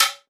Perc  (8).wav